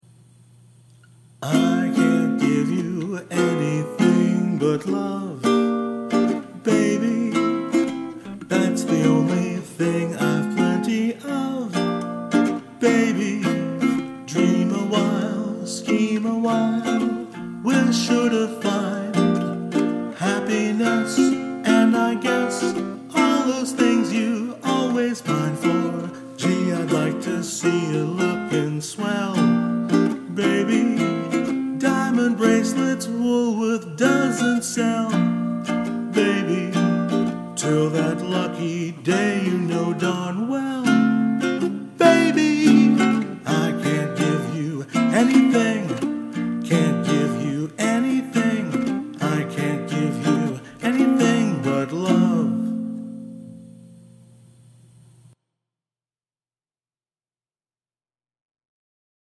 Category Archives: Ukelele